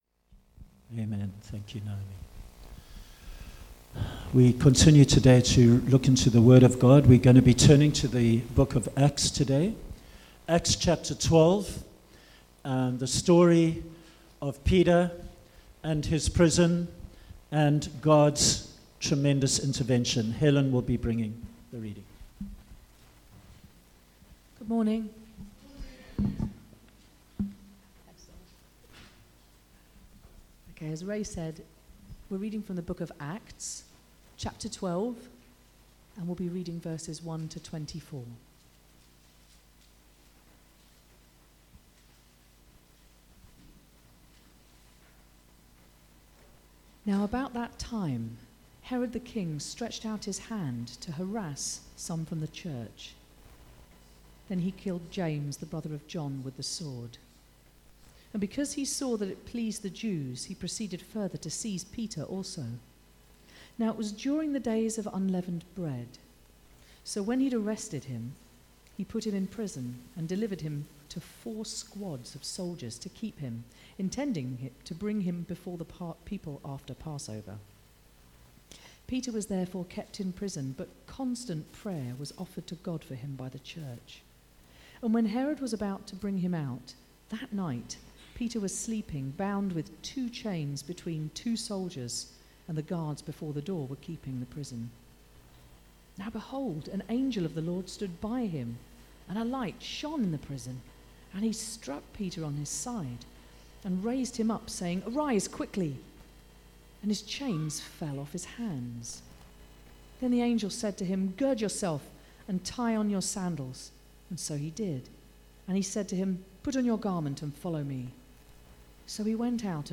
A Sunday sermon